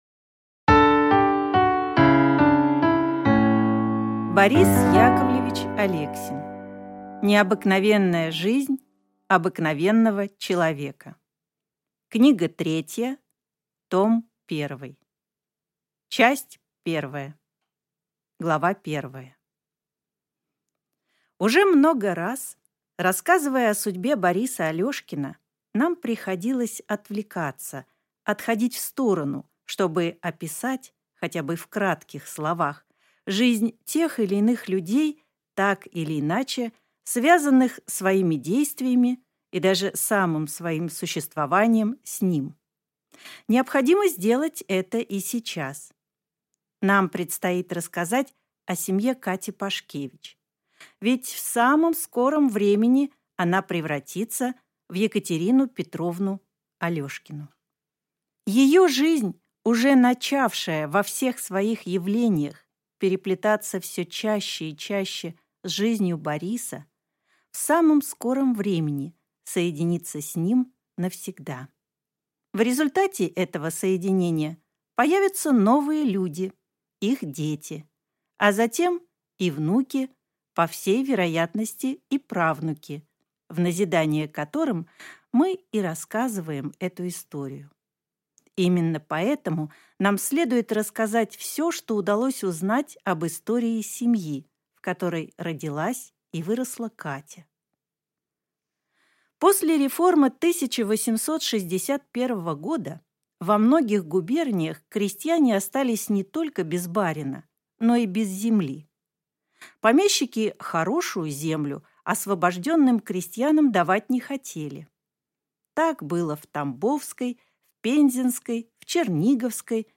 Аудиокнига Необыкновенная жизнь обыкновенного человека. Книга 3. Том 1 | Библиотека аудиокниг
Прослушать и бесплатно скачать фрагмент аудиокниги